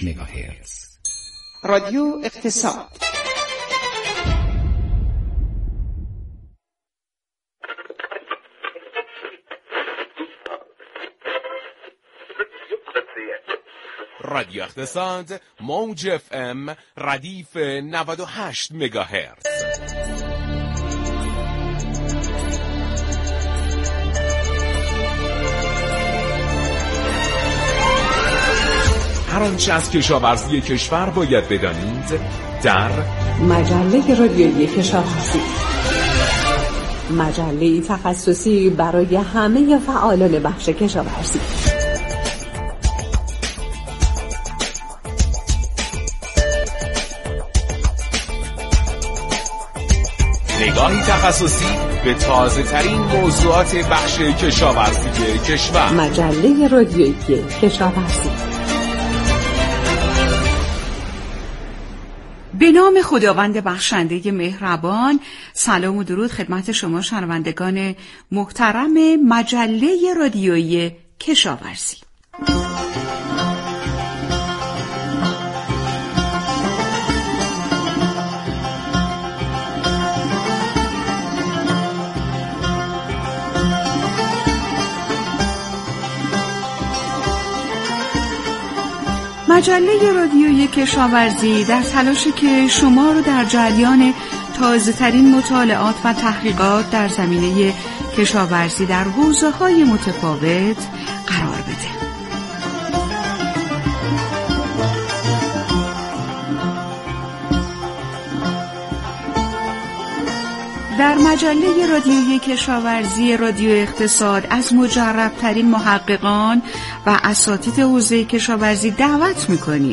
مصاحبه های علمی، فنی و ترویجی